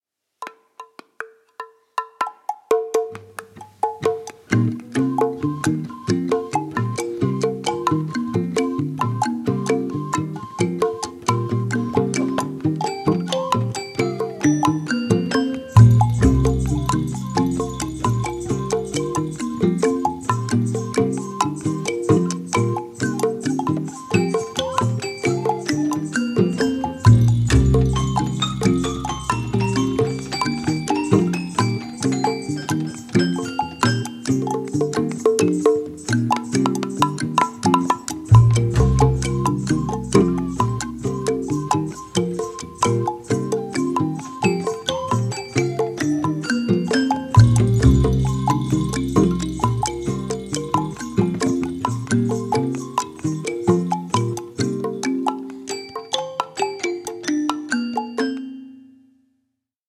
In order of recording: Kalimba (Mbira), Toy Piano, Recorder (3x), Tiny Bongo, Cowbell, Double Bass, Schellen-Dings, Glöckchen-Dings